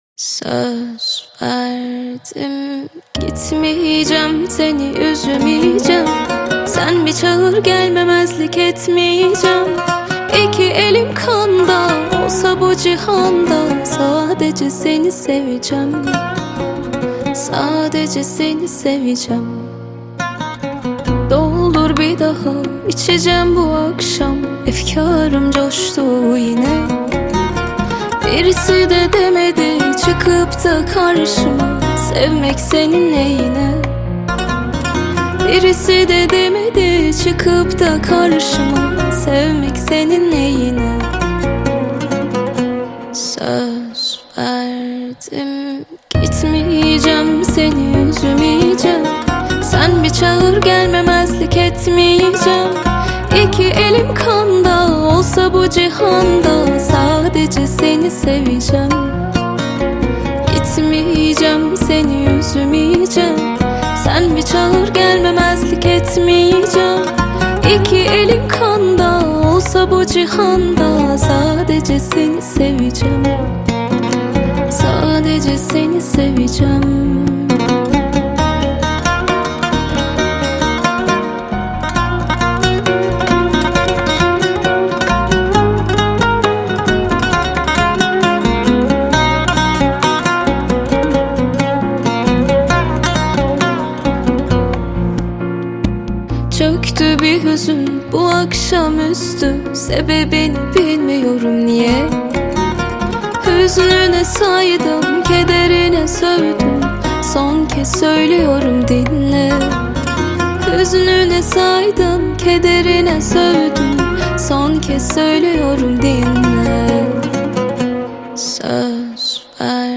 • Категория: Турецкая музыка